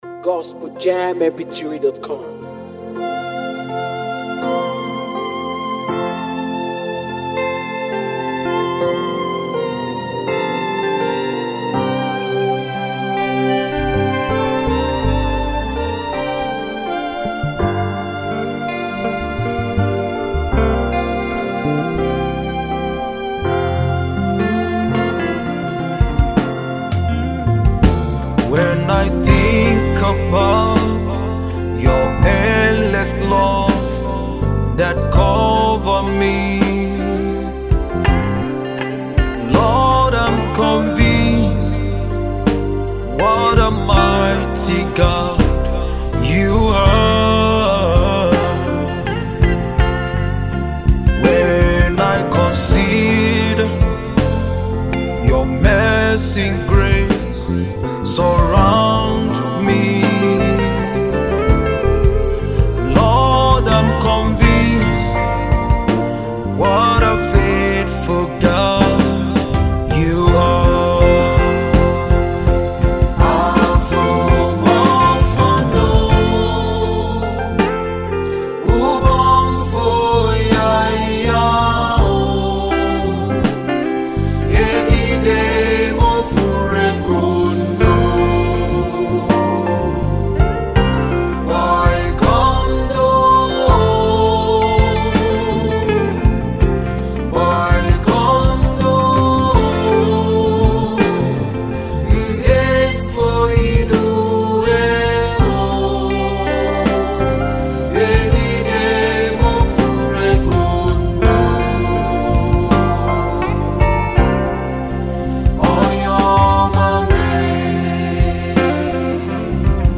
musicWorship